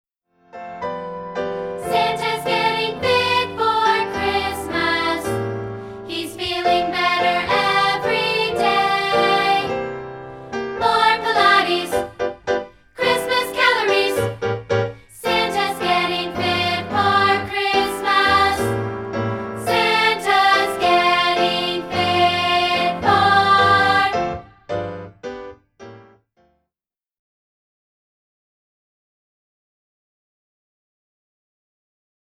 This energetic song